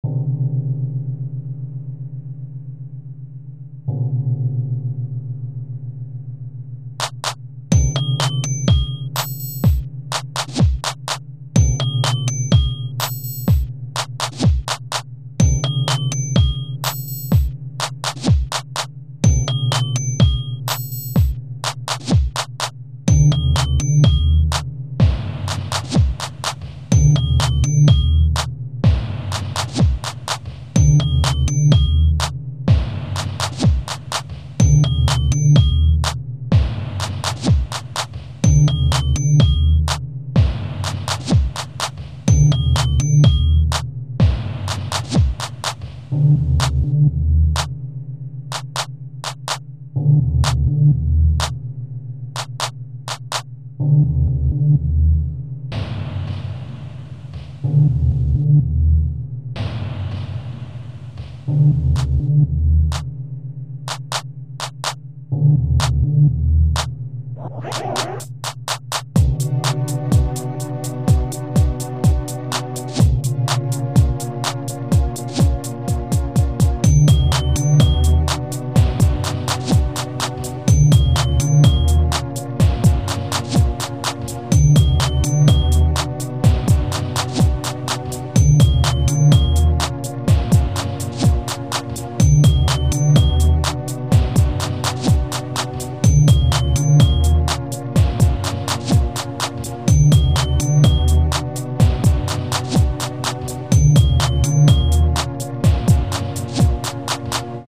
Вот начал музыку делать, в демке не одного семпла всё по нотам кроме 1 sfx звука